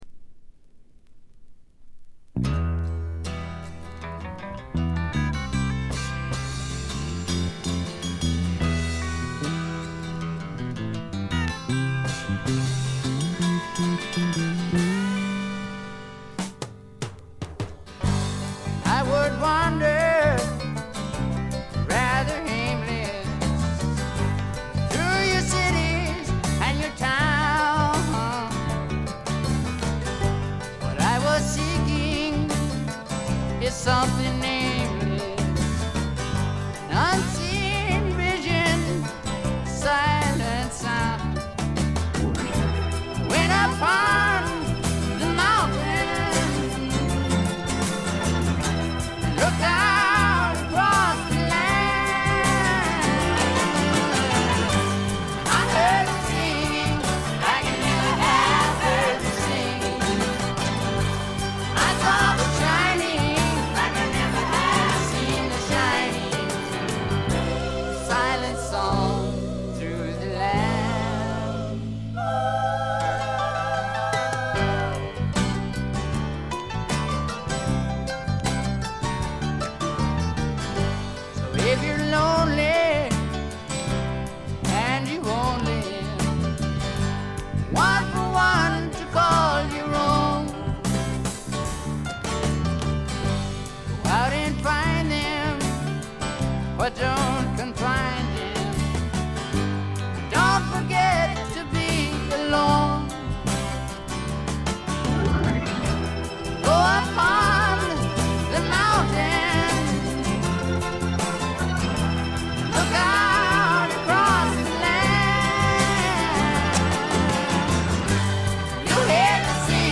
スワンプ路線とフォーキー路線が半々でどちらも素晴らしい出来ばえです。
試聴曲は現品からの取り込み音源です。
Vocals, Acoustic Guitar